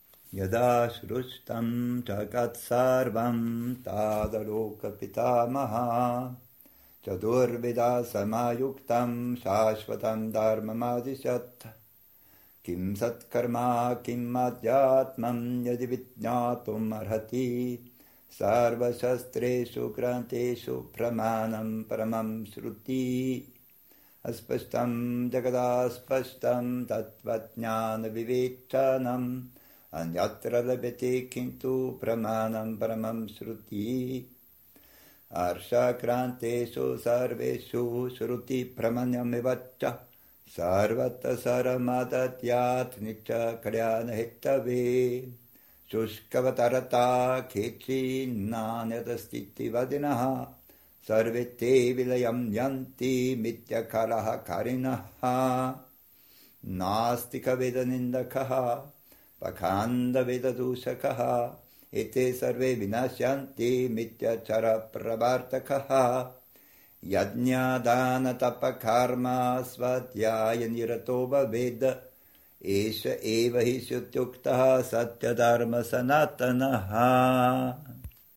Traditionell werden diese nach Agnihotra gesungen.